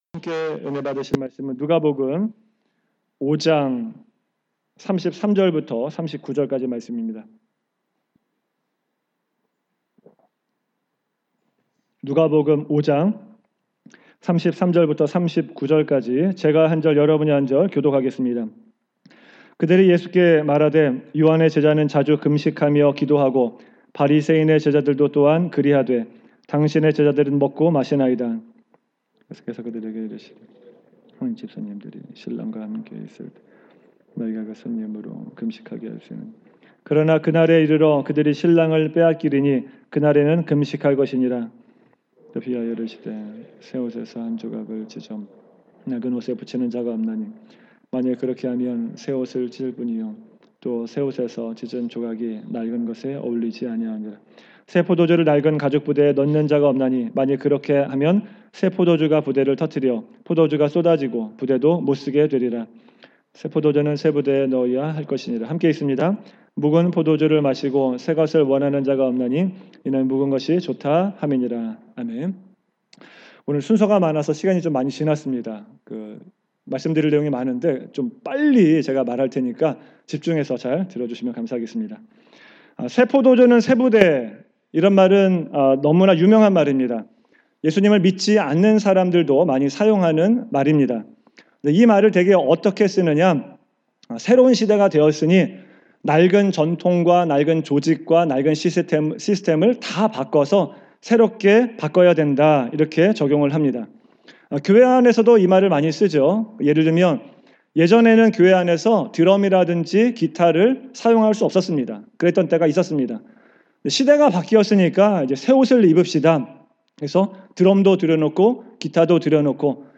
2019년 주일설교